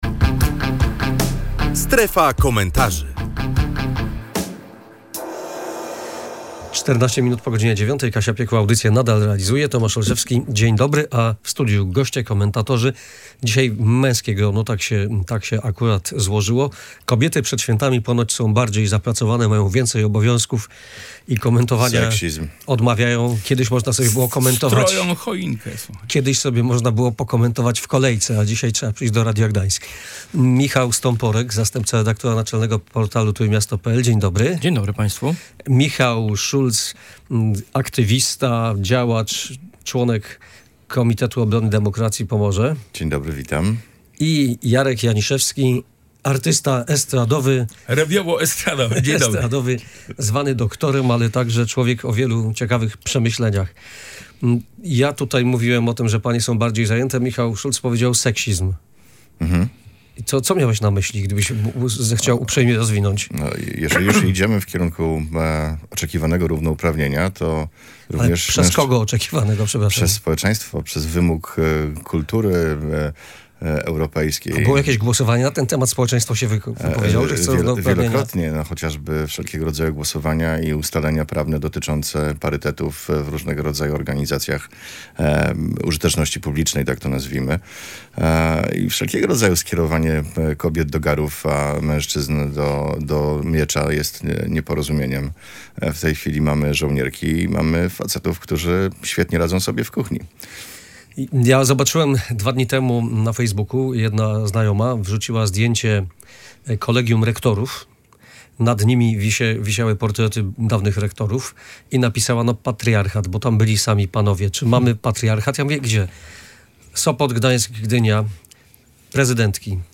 Dyskusja na temat seksizmu i patriarchatu w „Strefie Komentarzy”